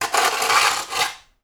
SPADE_Scrape_Asphalt_RR3_mono.wav